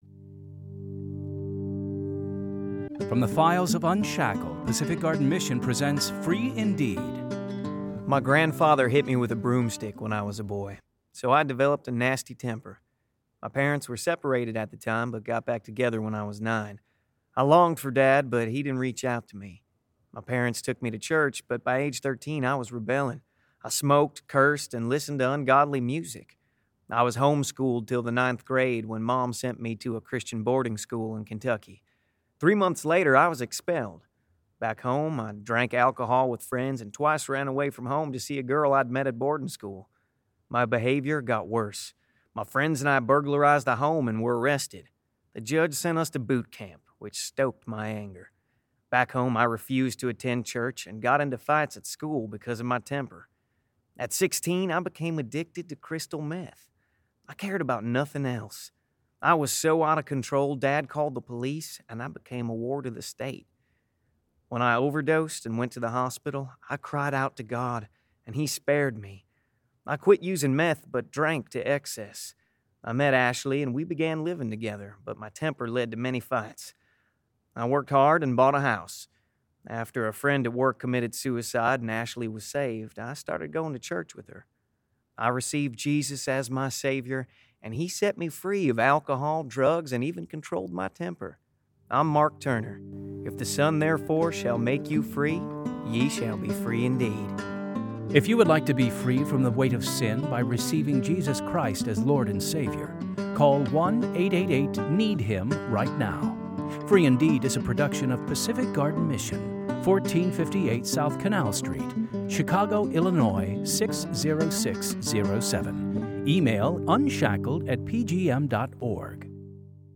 Two Minute Testimonies of True Life Stories